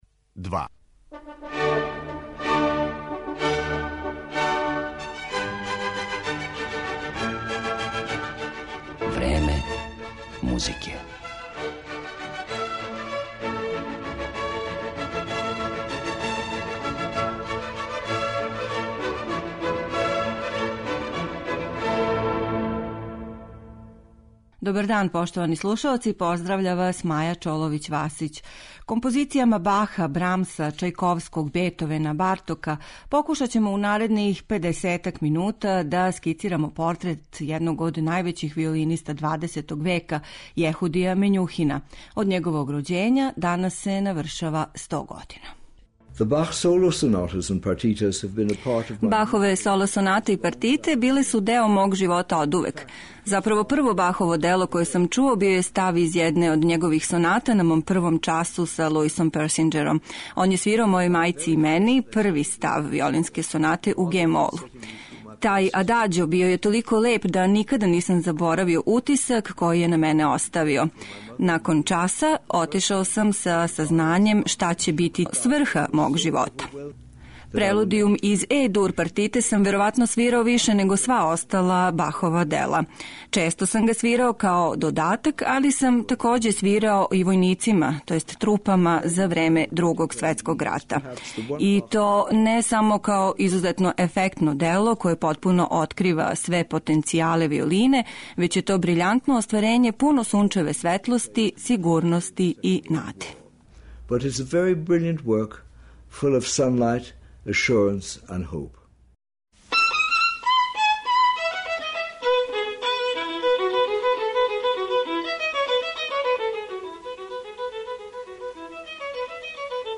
Своју је музику несебично делио током дуге и успешне каријере широм света: Свирам за све оне који су гладни и жедни музике, али и за оне који пате, без обзира на боју коже и порекло - речи су лорда Мењухина, чији ће портрет бити осликан музиком Баха, Елгара, Бартока ...